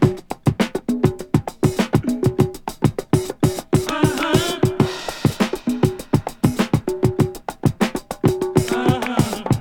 • 133 Bpm Breakbeat Sample F# Key.wav
Free drum loop sample - kick tuned to the F# note. Loudest frequency: 907Hz
133-bpm-breakbeat-sample-f-sharp-key-Dw7.wav